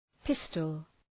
{‘pıstəl}